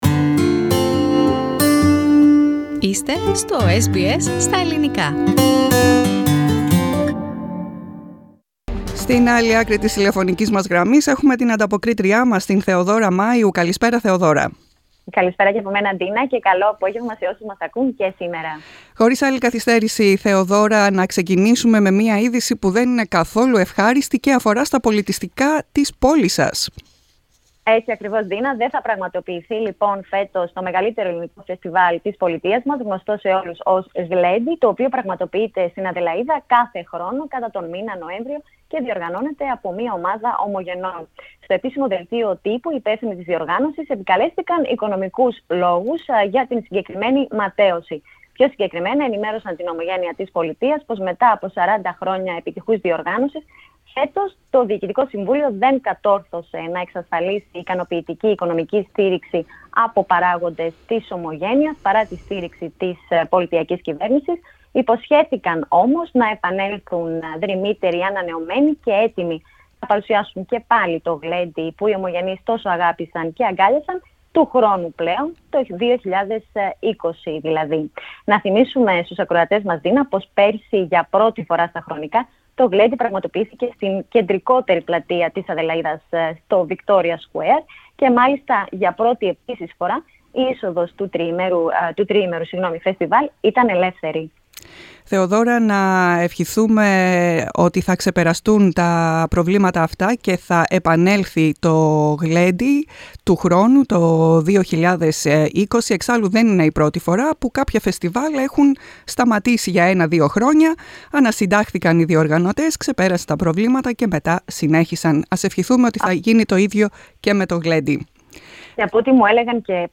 The weekly report form Adelaide.